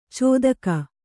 ♪ cōdaka